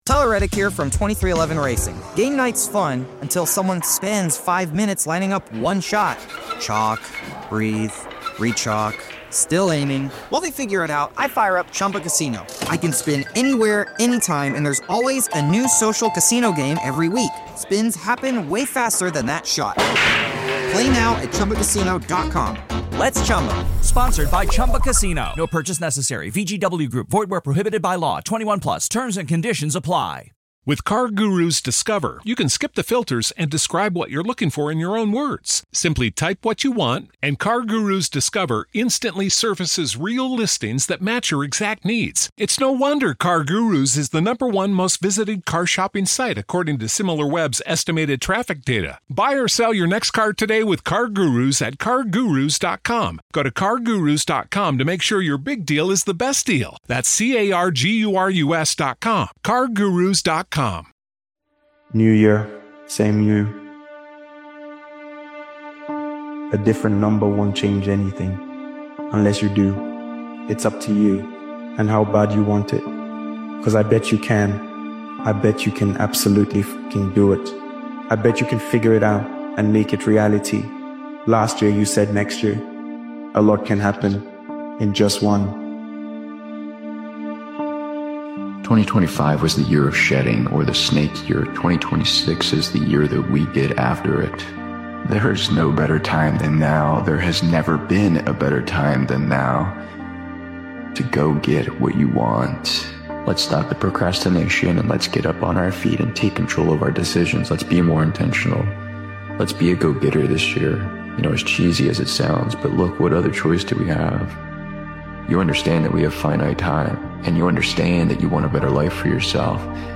This powerful motivational speeches compilation delivers a simple truth: transformation doesn’t start with luck, timing, or motivation—it starts wi...